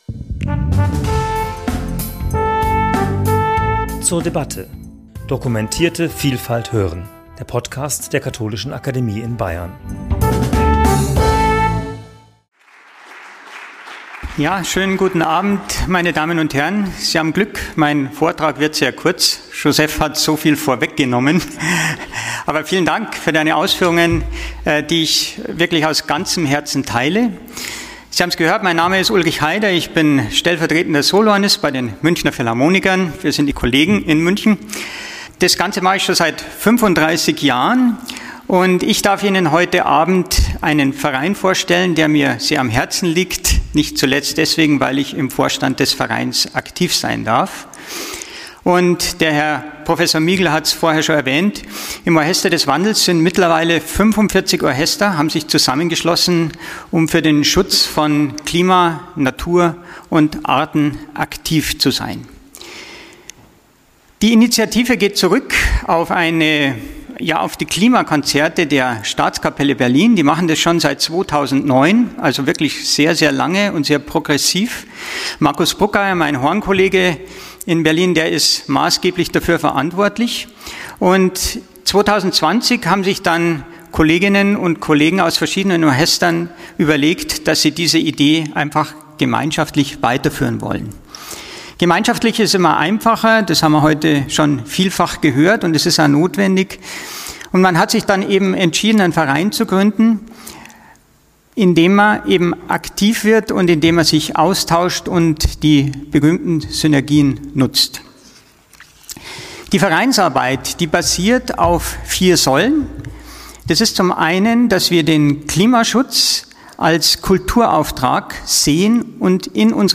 Vortrag
Franziskus’ Erbe für die Schöpfung' bei der Studientagung 'Laudato si’ und die ökologische Transformation' am 2.10.2025 in der Katholischen Akademie in Bayern.